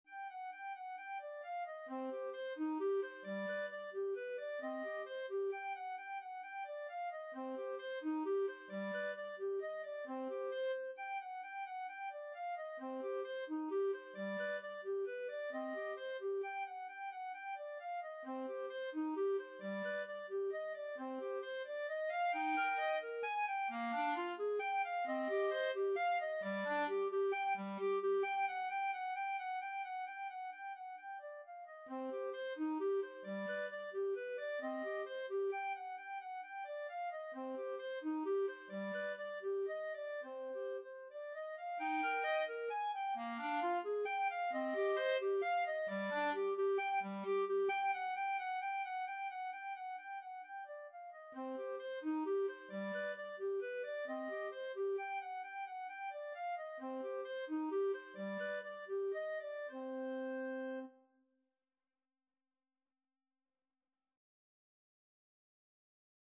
Free Sheet music for Clarinet Duet
C minor (Sounding Pitch) D minor (Clarinet in Bb) (View more C minor Music for Clarinet Duet )
=132 Moderato
3/4 (View more 3/4 Music)
Clarinet Duet  (View more Intermediate Clarinet Duet Music)
Classical (View more Classical Clarinet Duet Music)